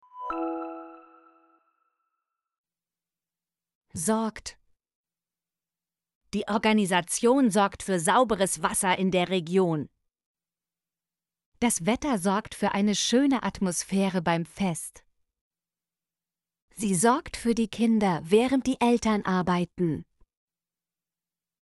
sorgt - Example Sentences & Pronunciation, German Frequency List